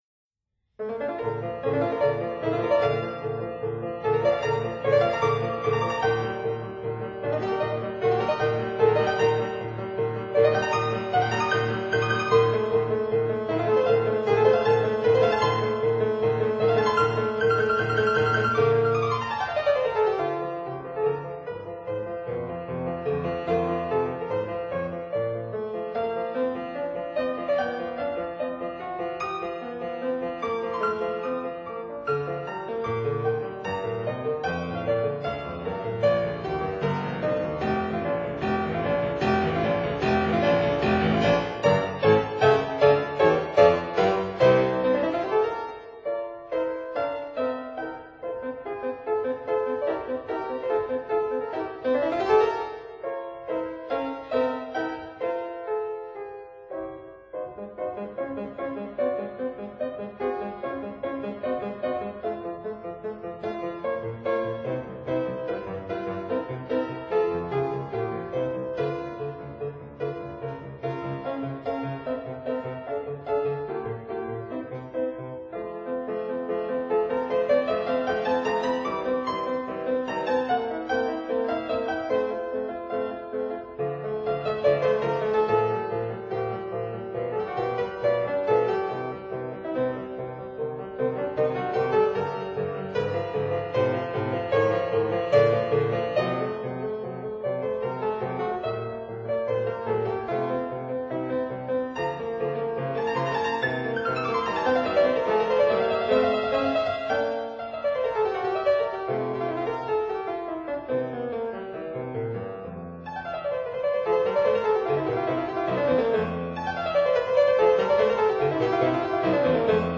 Fortepiano
(Period Instruments)